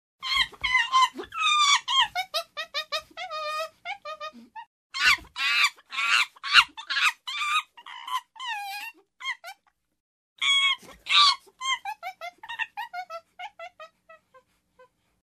• Качество: высокое
Звук крика шимпанзе